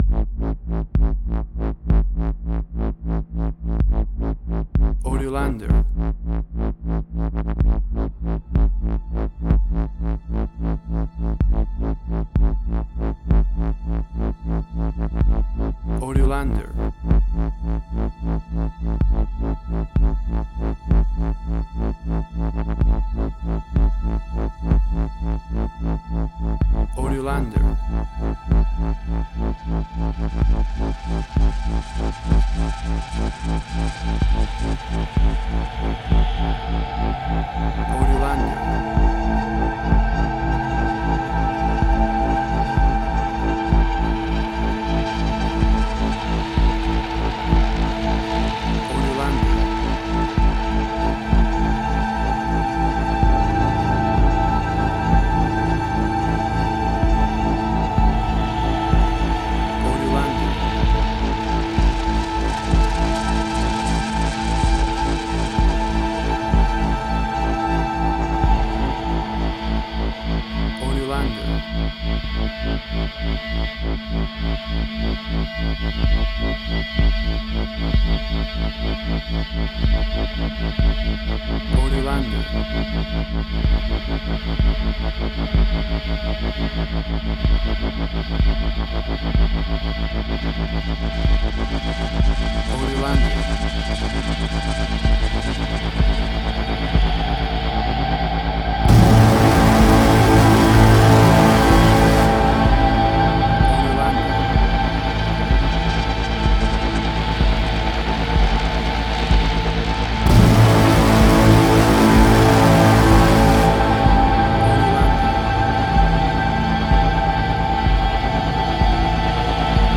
Post-Electronic.
Tempo (BPM): 126